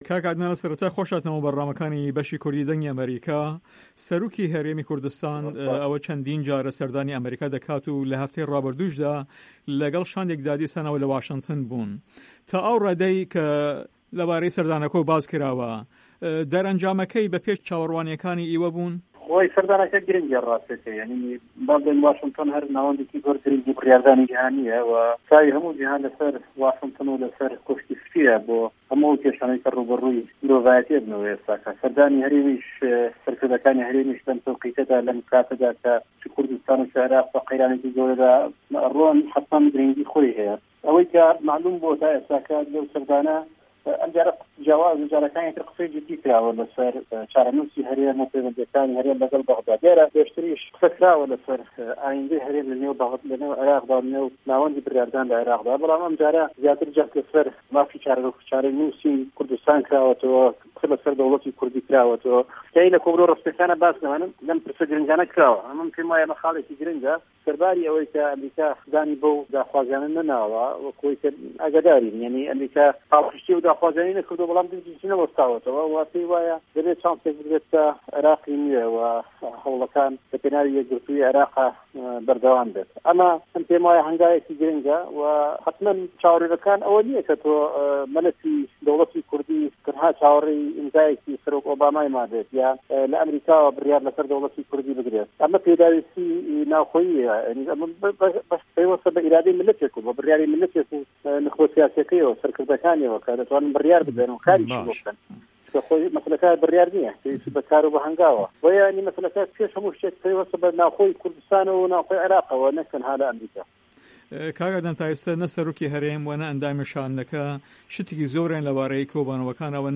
عه‌دنان عوسمان ئه‌ندامی خولی پێشووی په‌رله‌مانی هه‌رێمی کوردستان له‌ هه‌ڤپه‌یڤێنێکدا له‌گه‌ڵ به‌شی کوردی ده‌نگی ئه‌مه‌ریکا ده‌ڵێت" راستیه‌که‌ی سه‌ردانه‌که گرنگه‌، له‌به‌ر واشنتن ناوه‌ندیه‌کی زۆر گرنگی بریاردانی جیهانیه‌ وه‌ چاوی هه‌موو جیهان له‌سه‌ر واشنتن و کۆشکی سپی دایه‌ بۆ هه‌موو ئه‌و کێشانه‌ی روبه‌روو مرۆڤایه‌تی ده‌بنه‌وه‌.